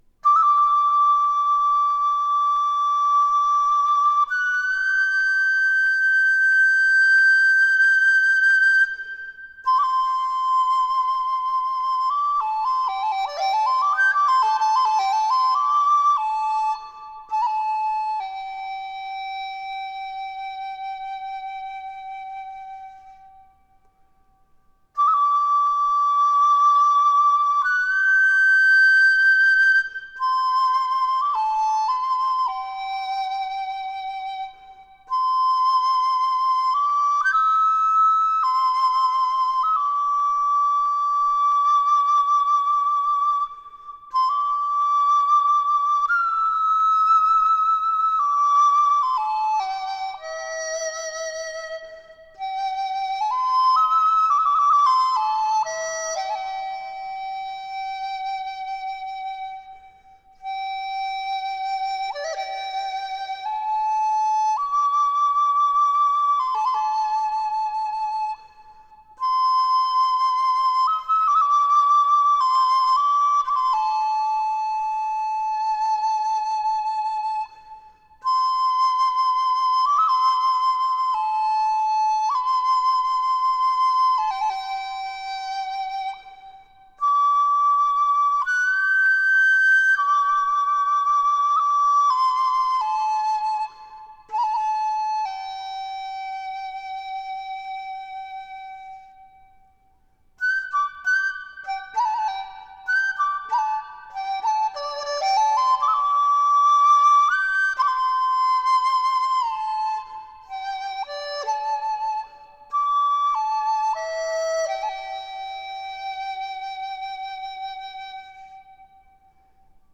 Китайская музыка Медитативная музыка Флейта